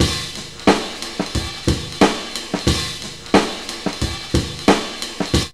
JAZZLP1 90.wav